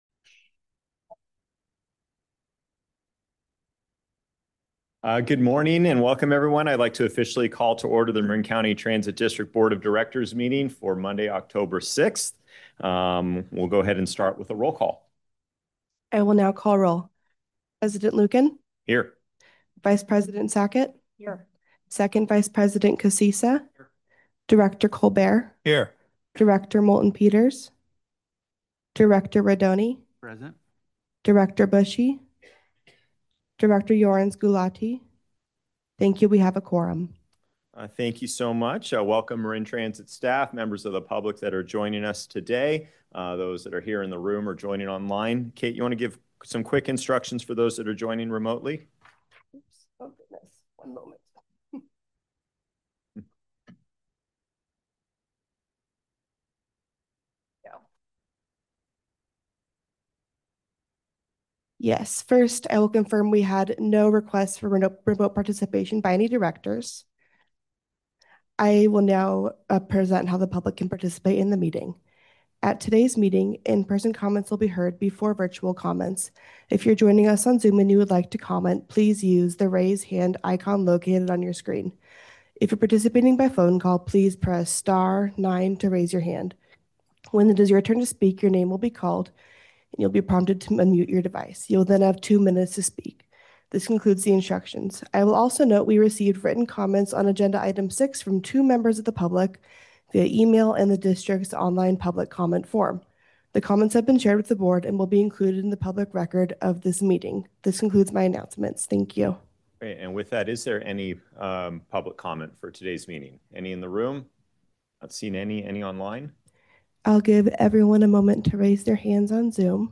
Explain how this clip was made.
Members of the public can provide comment during open time and on each agenda item when the Board President calls for public comment. In-person comments will be heard before virtual comments.